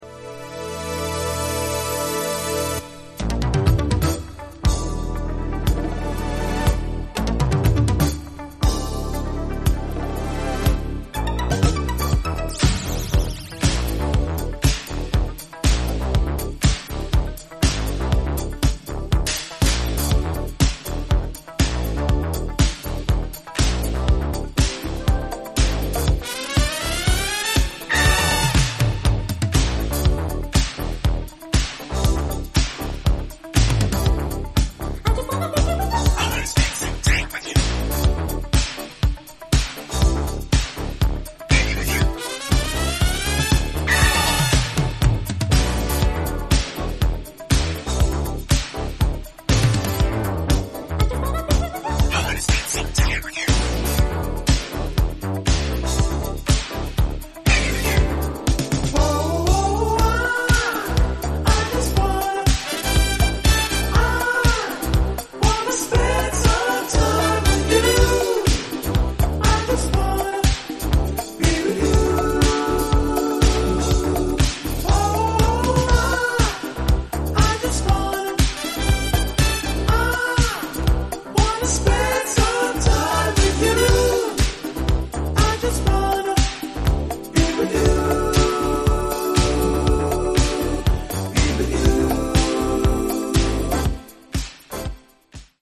club anthem